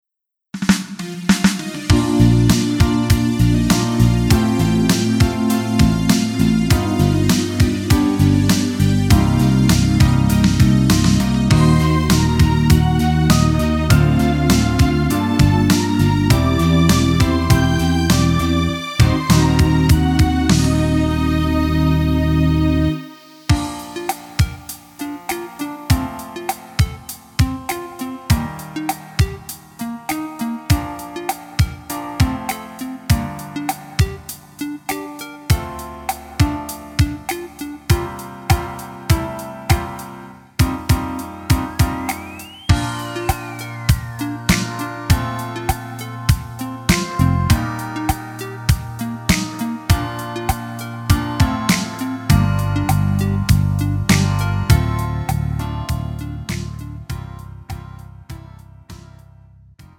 음정 -1키
장르 구분 Pro MR